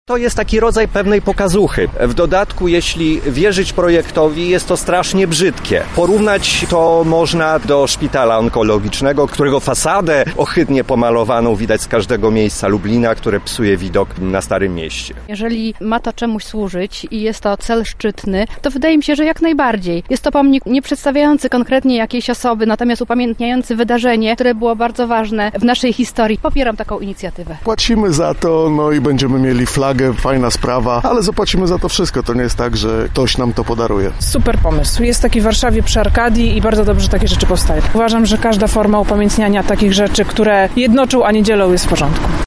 Sonda maszt